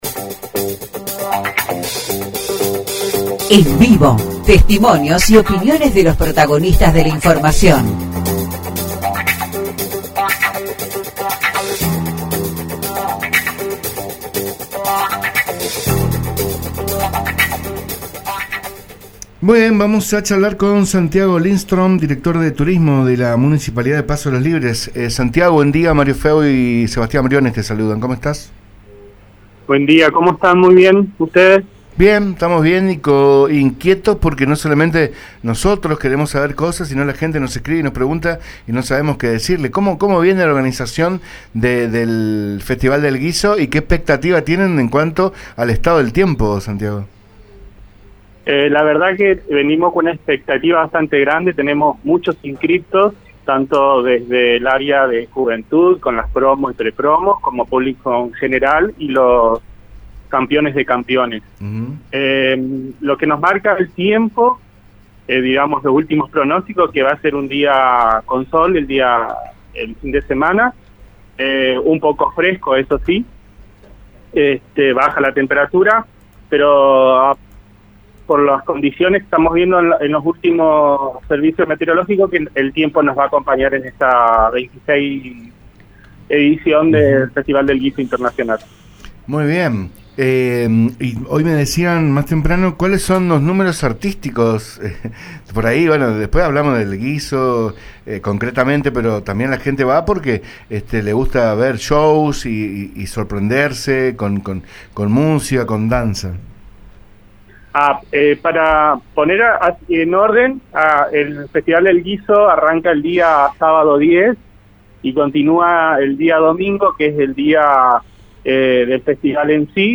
en PONELE H Radio fue entrevistado